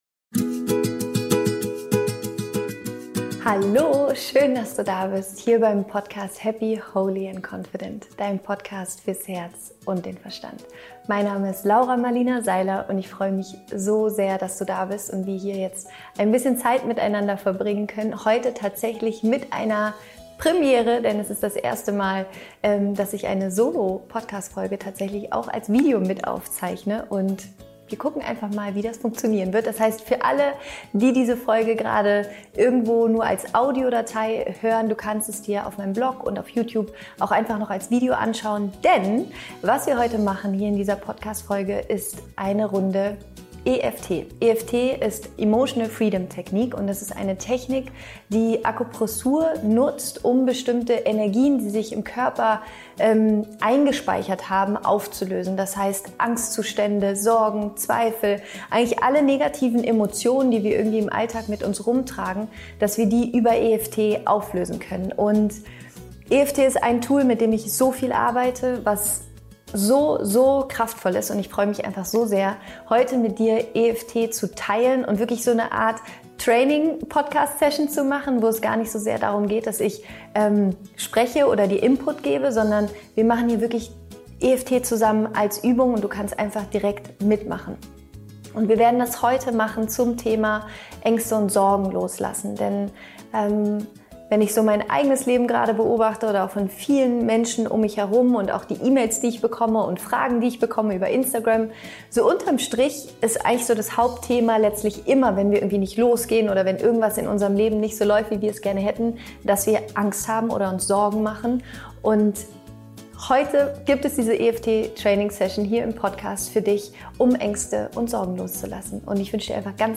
Finde einfach einen bequemen Sitz und ich werde dich durch diese EFT Session leiten.